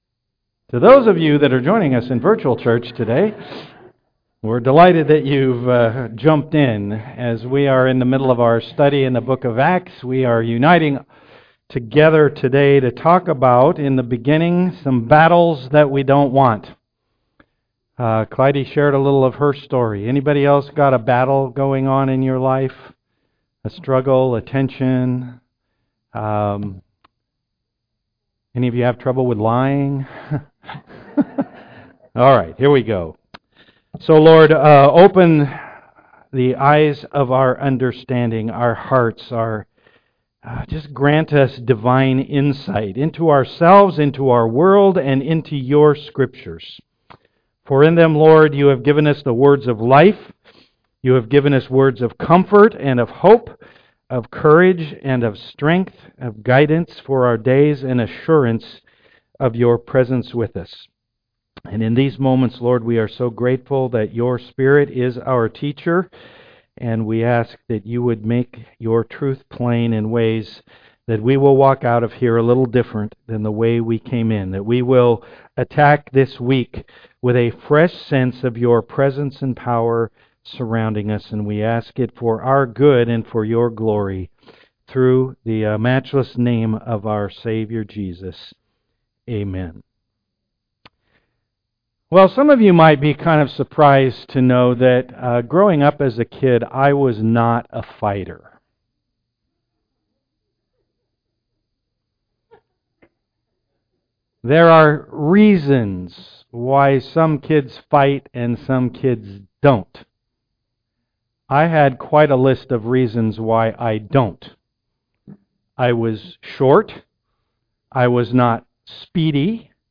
Acts 21:17-36 Service Type: am worship We all end up in battles we weren't looking for and don't want.